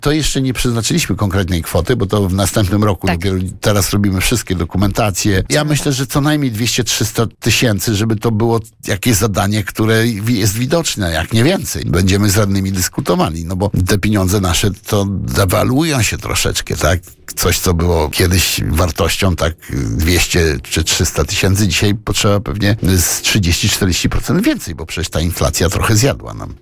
GD: Andrzej Duda, burmistrz Kolna